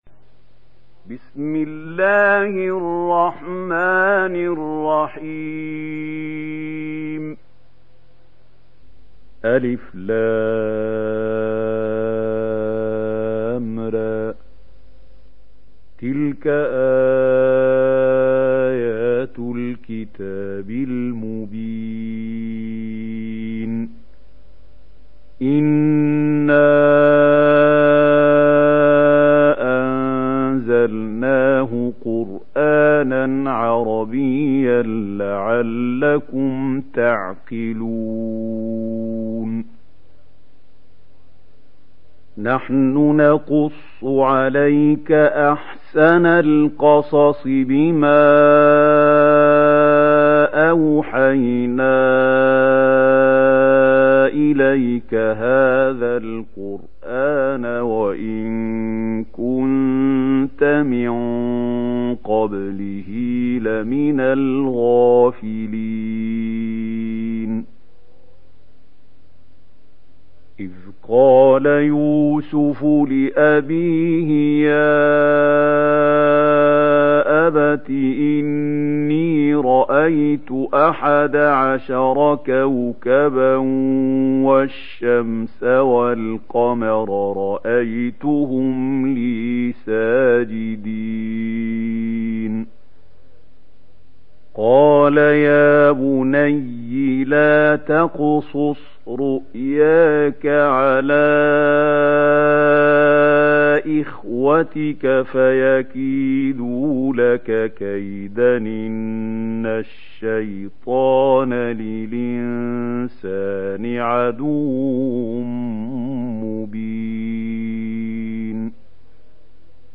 دانلود سوره يوسف mp3 محمود خليل الحصري روایت ورش از نافع, قرآن را دانلود کنید و گوش کن mp3 ، لینک مستقیم کامل